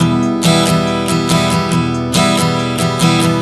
Strum 140 Em 01.wav